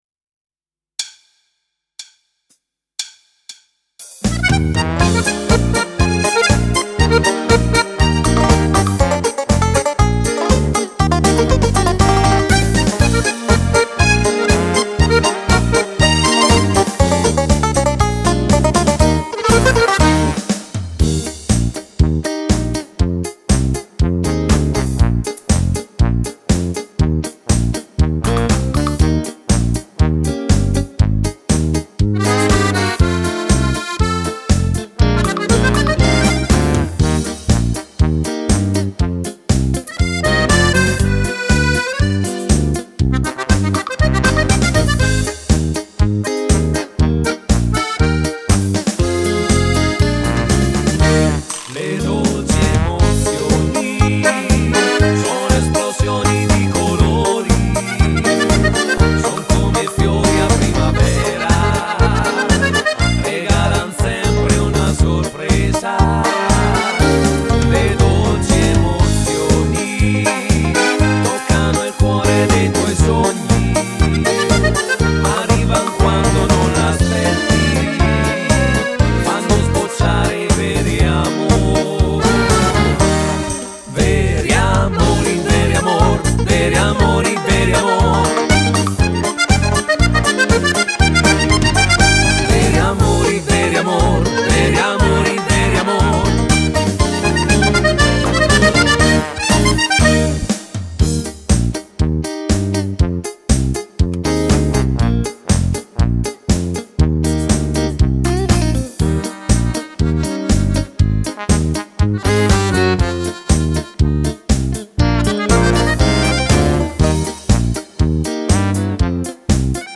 Country polca
Donna